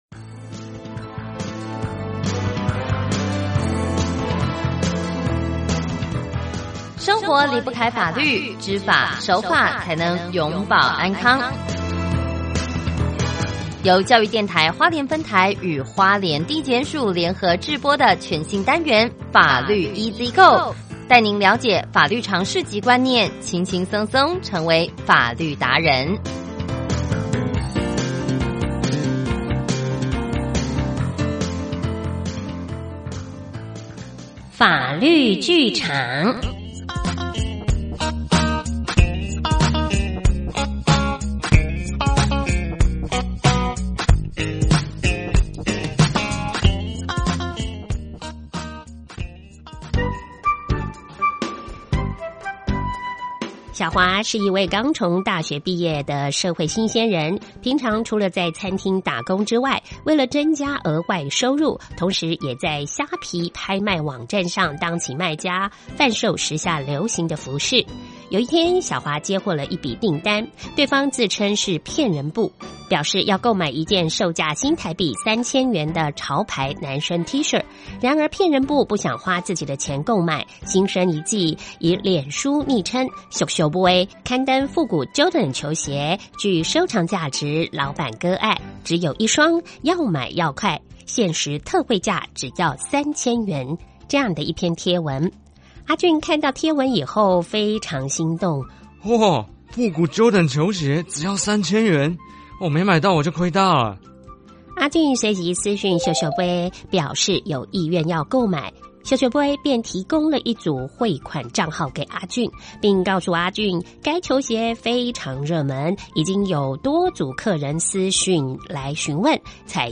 (音效：電腦打字)隨即私訊「俗俗賣」，表示有意願購買，「俗俗賣」便提供一組匯款帳號給阿俊，並告訴阿俊該球鞋非常熱門，已經有多組客人私訊詢問，採先付款後出貨的方式。